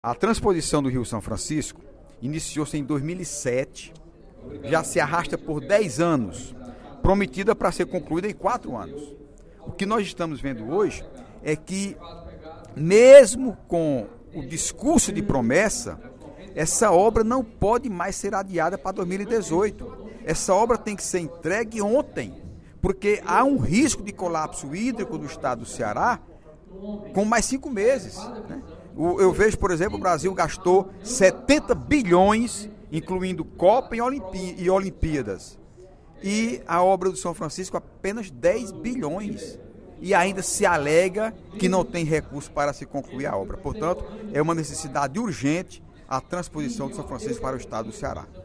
O deputado Heitor Férrer (PSB) defendeu, durante o primeiro expediente da sessão plenária desta quarta-feira (15/03), a conclusão das obras de transposição do rio São Francisco. Ele avaliou que o empreendimento ainda não foi concluído por uma inversão de prioridades dos governantes.